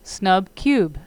(snub  cube)